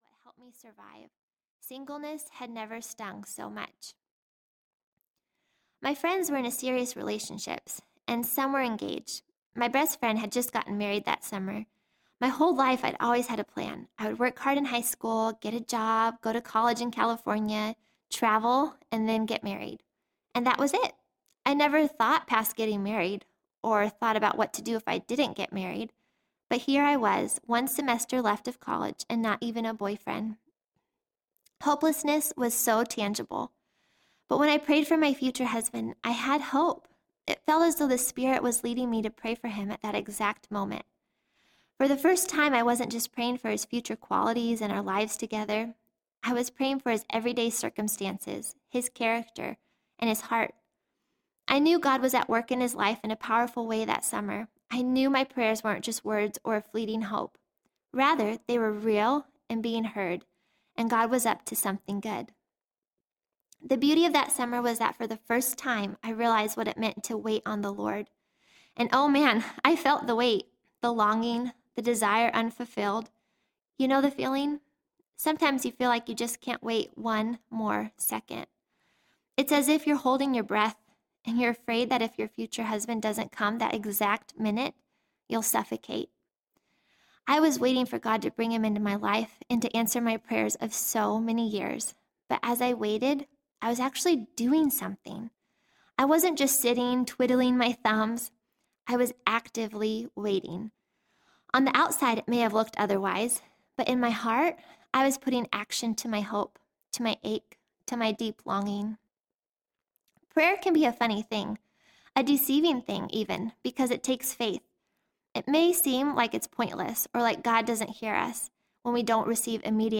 Love That Lasts Audiobook